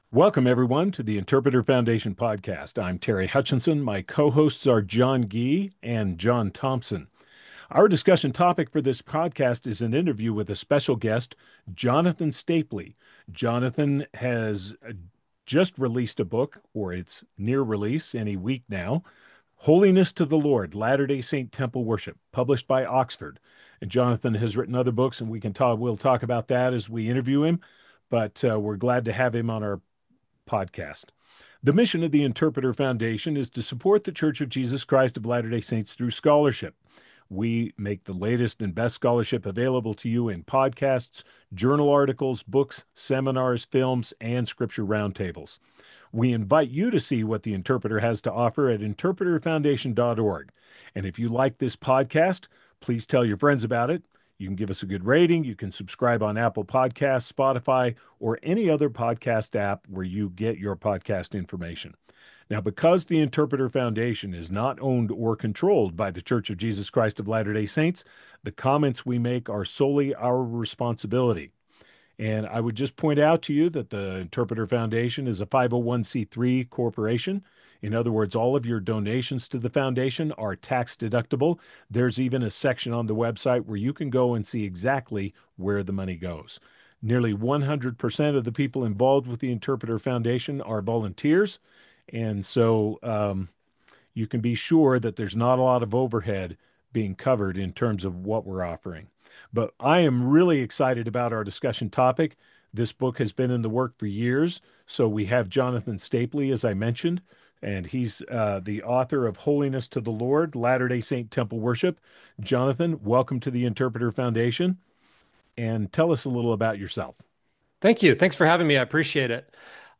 You can listen to or download the discussion segment of the podcast episode below.